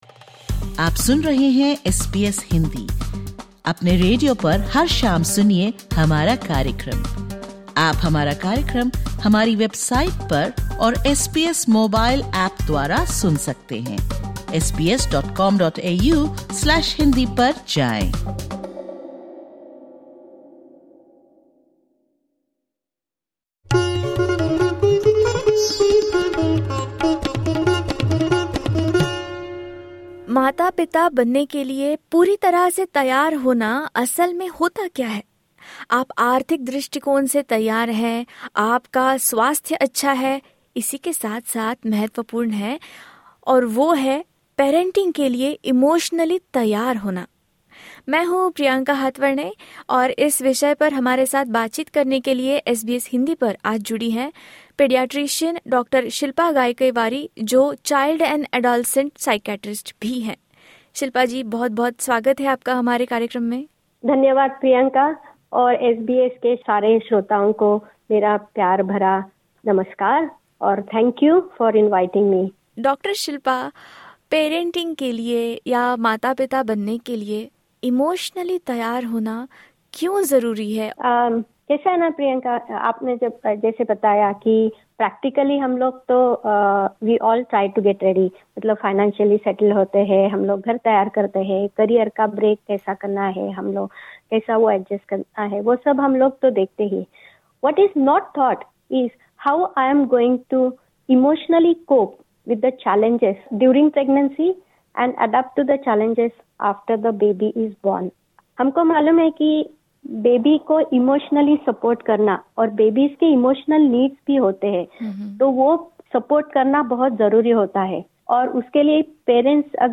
DISCLAIMER: The information expressed in this interview is of general nature.